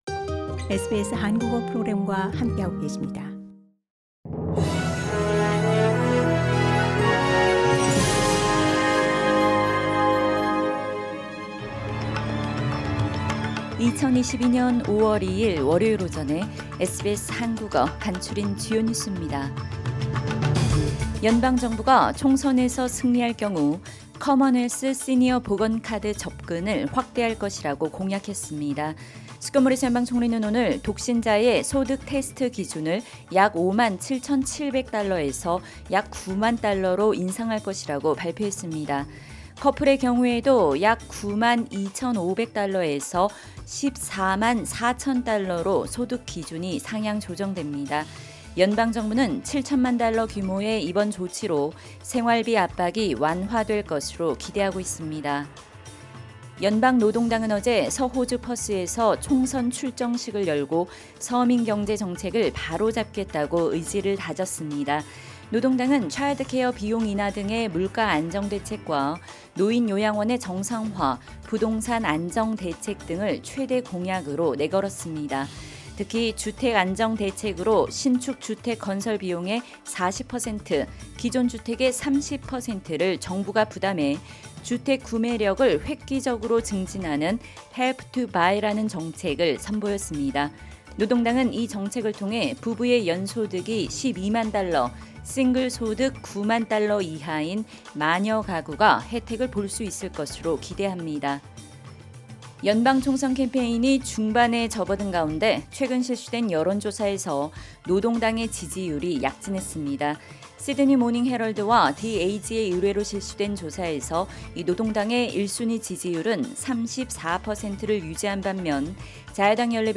SBS 한국어 아침 뉴스: 2022년 5월 2일 월요일